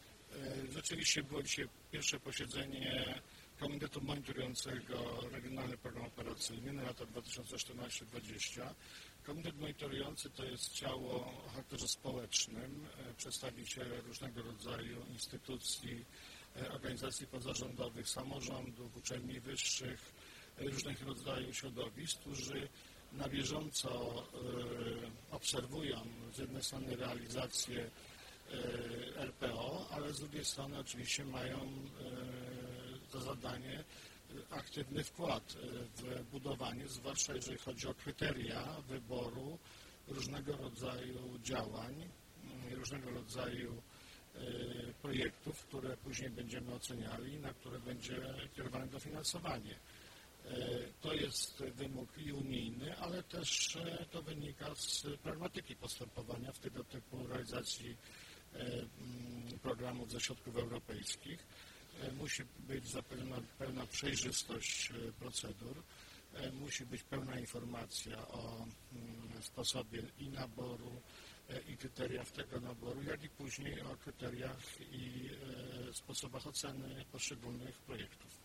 Posłuchaj wypowiedzi Adama Struzika - Marszałka Województwa Mazowieckiego<<